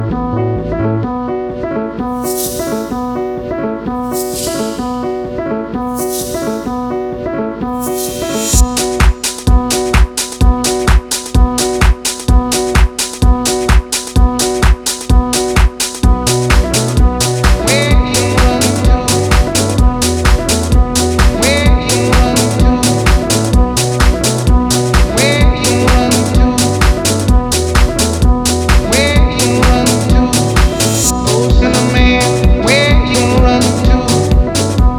# Jazz